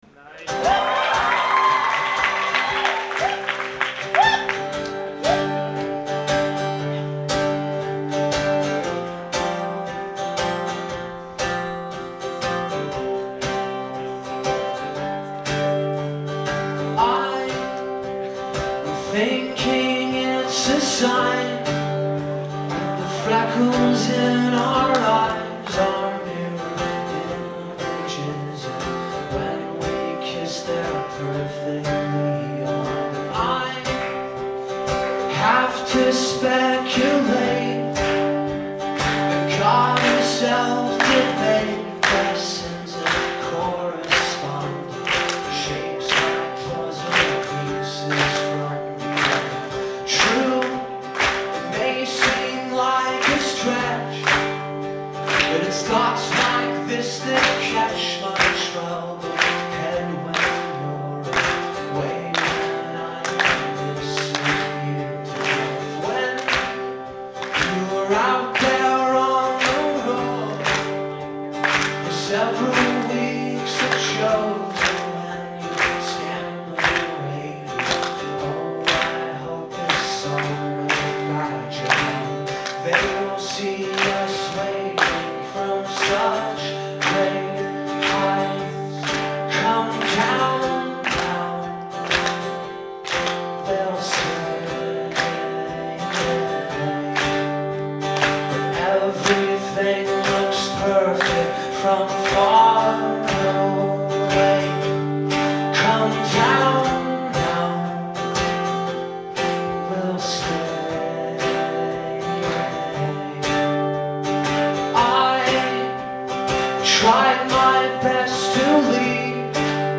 Live at the Somerville Theatre
in Somerville, Massachusetts